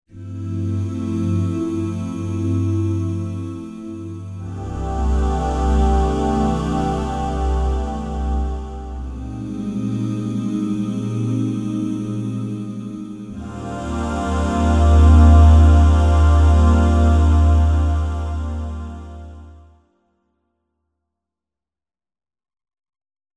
DooDah Mixed Pad Demo=3-C01.mp3